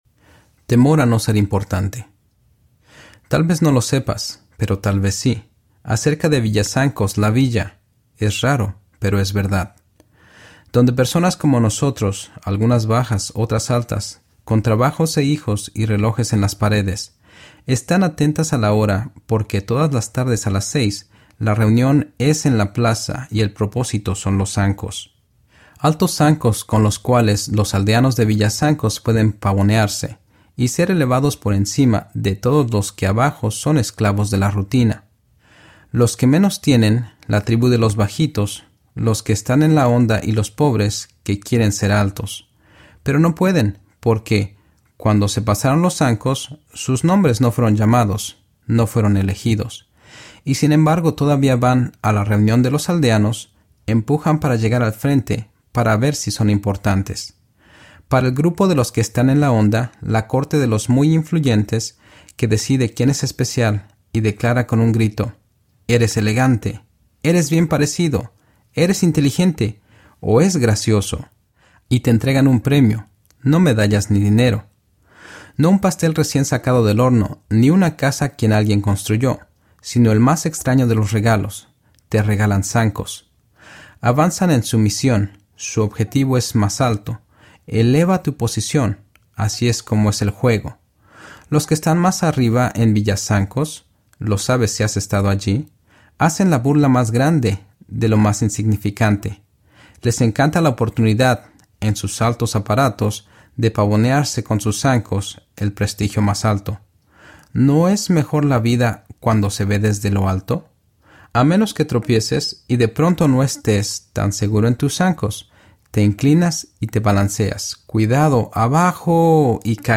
Sin Temor Audiobook
4.5 Hrs. – Unabridged